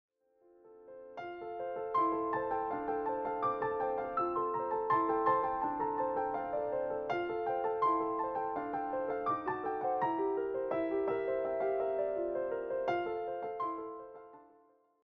all arranged and performed as solo piano pieces.